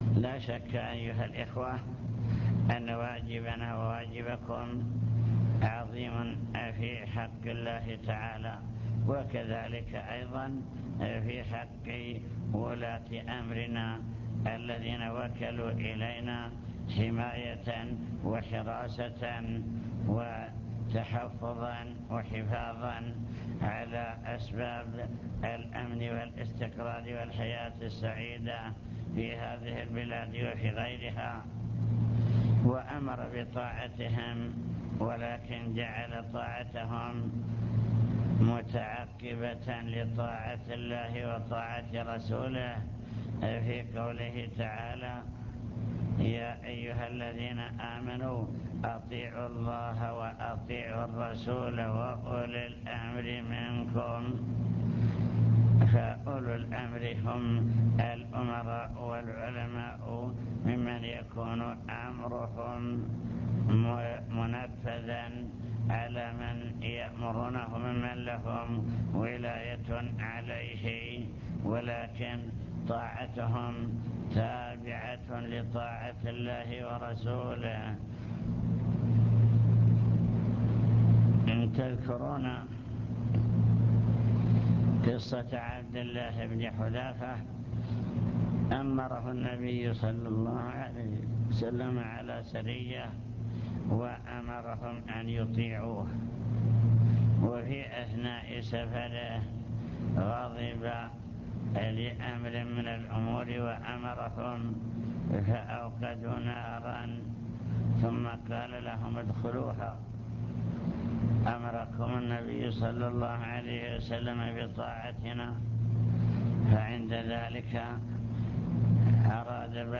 المكتبة الصوتية  تسجيلات - محاضرات ودروس  محاضرة في بدر بعنوان: وصايا عامة